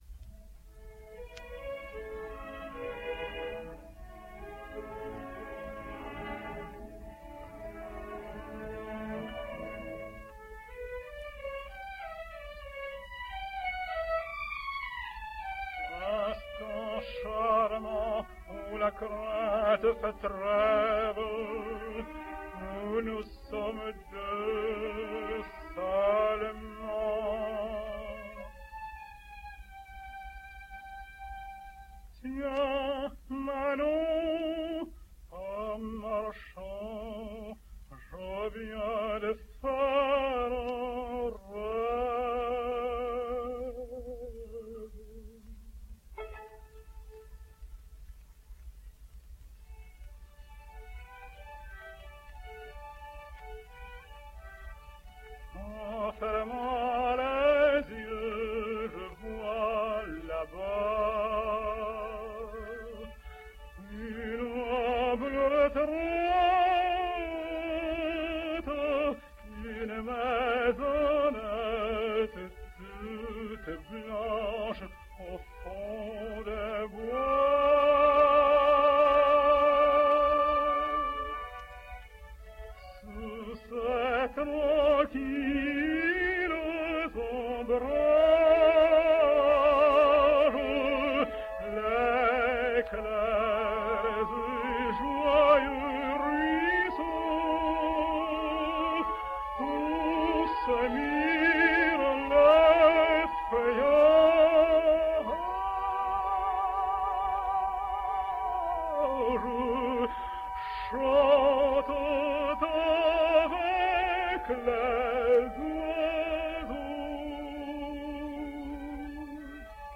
Russian Tenor.
Here then is his lovely recording of the dream from Masnis Manon.